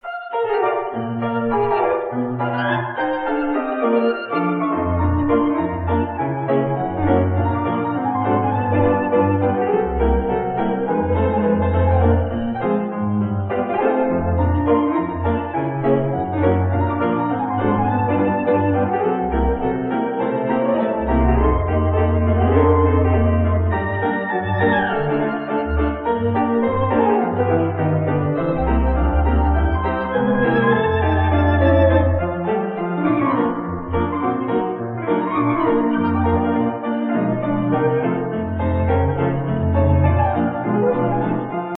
Recorded circa 1968
The musical arrangements are both charming and cheerful